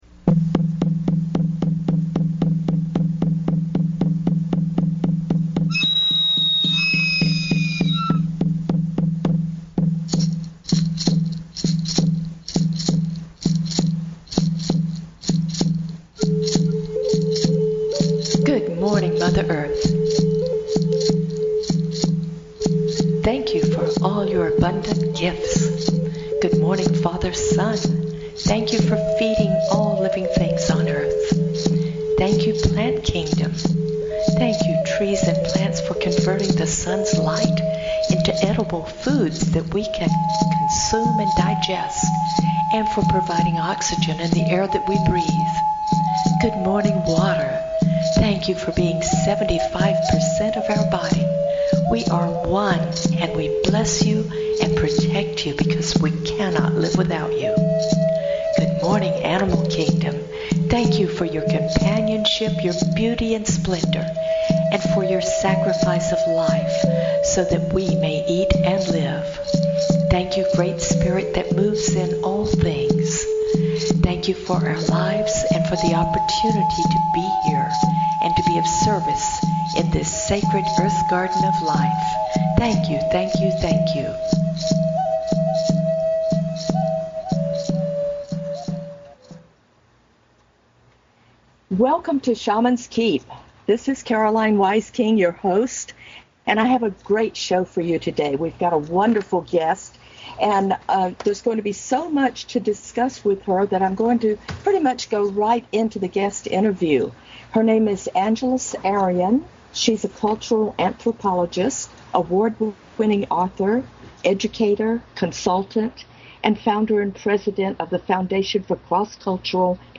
Guest, Angeles Arrien